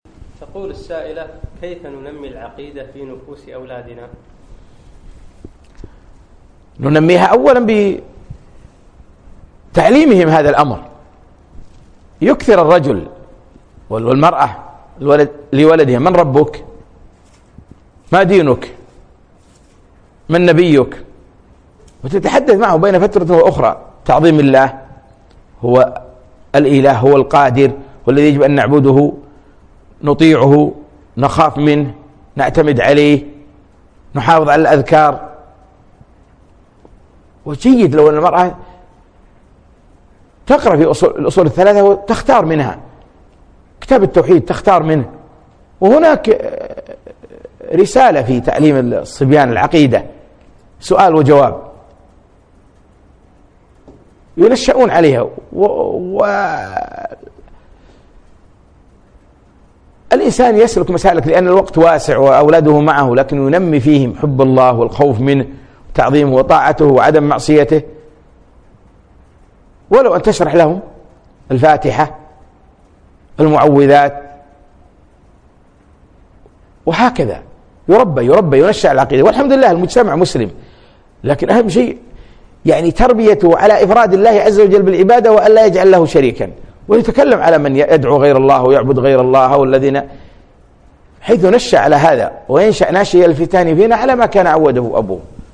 من محاضرة وقفات من سورة النور أقيمت في مركز نعيمة الدبوس صباحي الاثنين 3 4 2017